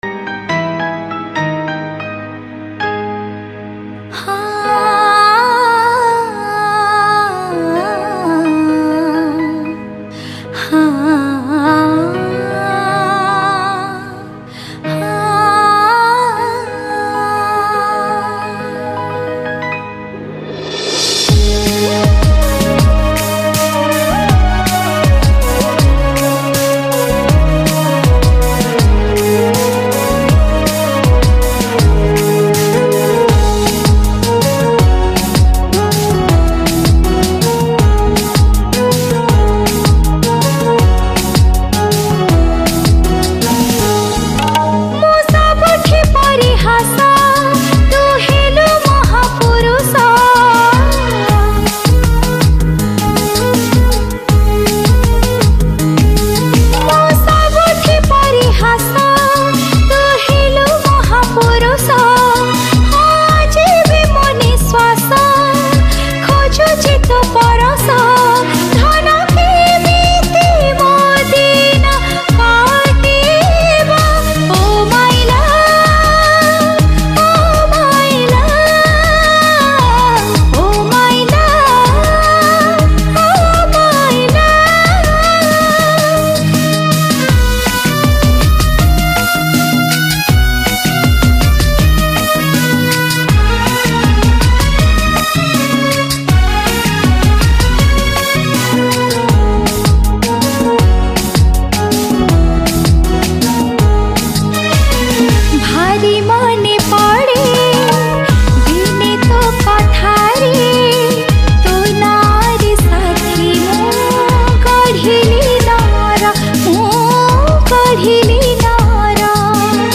New Sad Odia Song